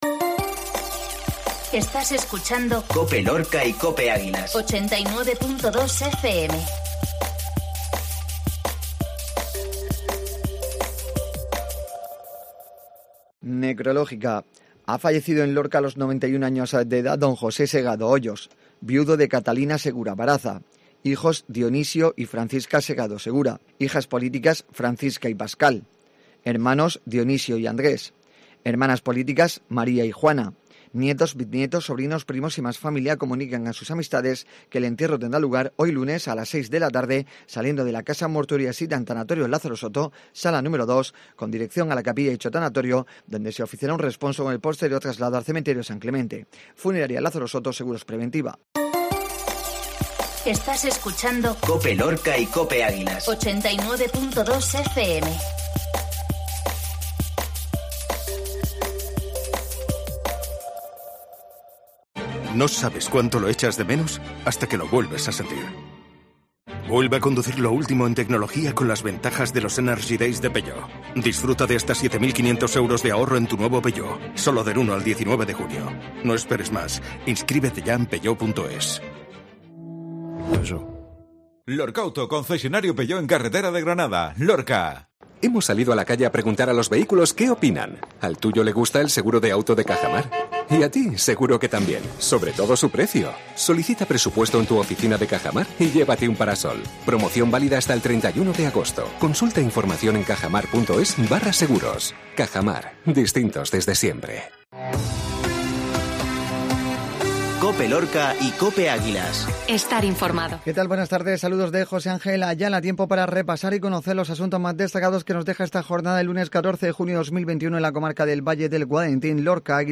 INFORMATIVO LUNES MEDIODÍA